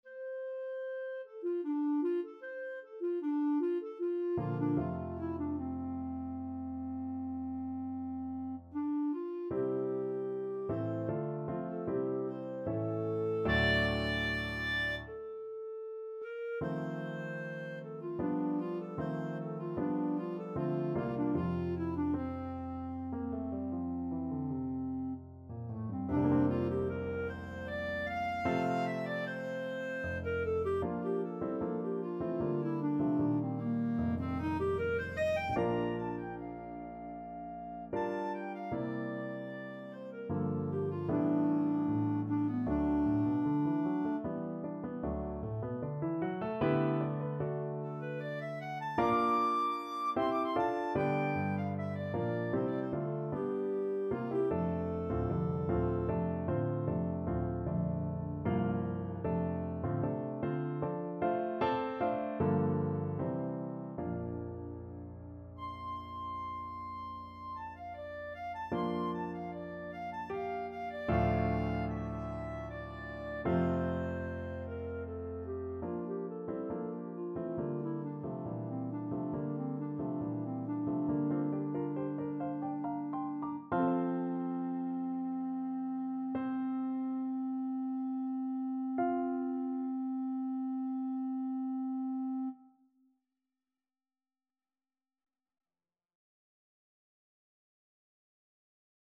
ClarinetPiano
3/4 (View more 3/4 Music)
Trs calme et doucement expressif =76
Classical (View more Classical Clarinet Music)